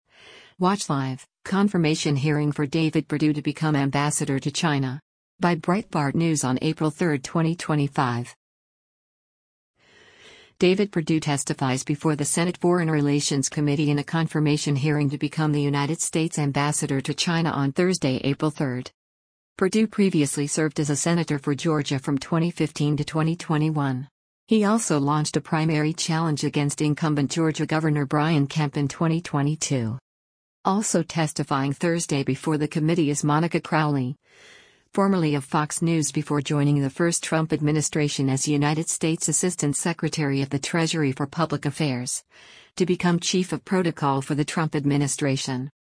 David Perdue testifies before the Senate Foreign Relations Committee in a confirmation hearing to become the United States ambassador to China on Thursday, April 3.